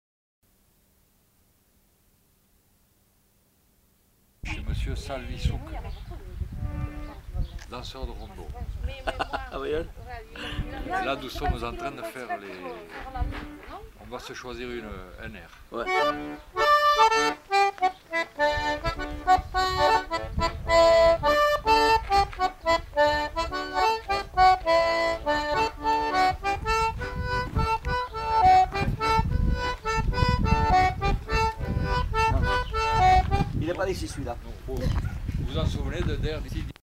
Aire culturelle : Savès ; Armagnac
Lieu : Masseube
Genre : morceau instrumental
Instrument de musique : accordéon diatonique
Danse : rondeau